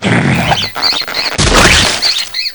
cage.wav